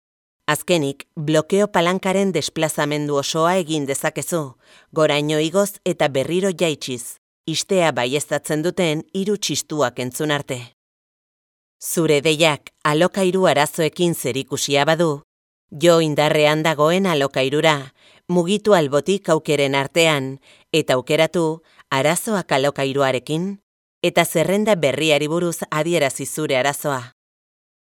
Basque female voice overs